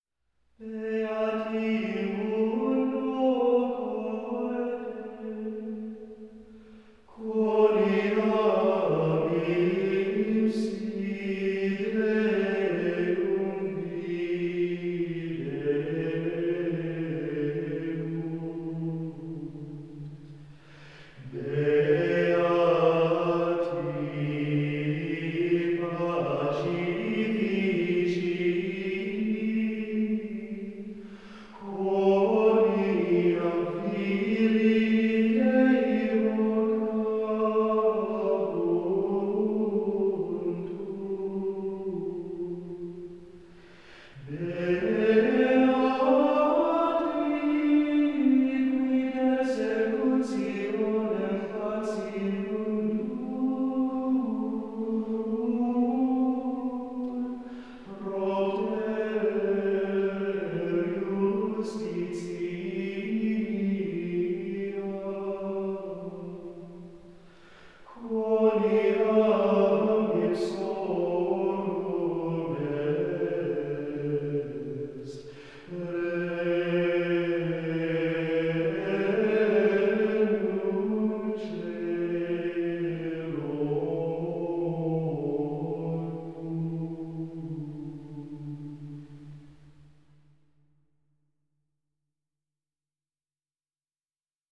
Communion chant